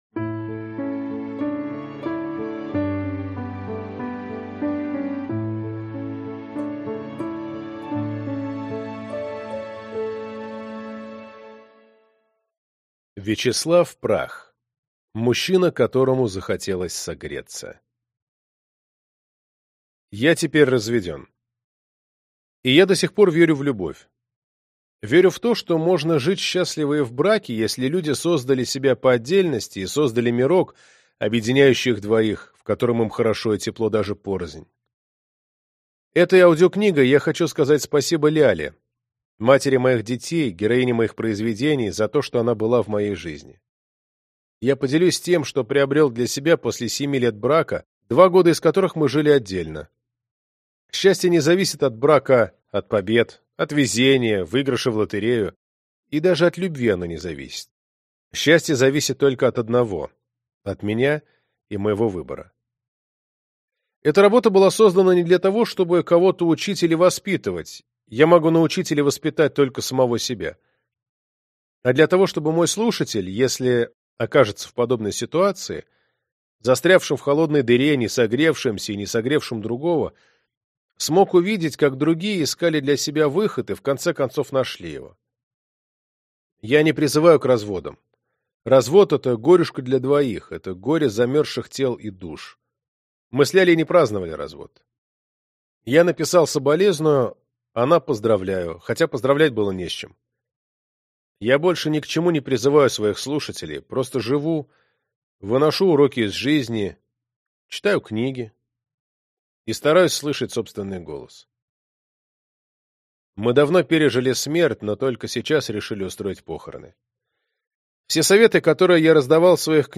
Аудиокнига Мужчина, которому захотелось согреться | Библиотека аудиокниг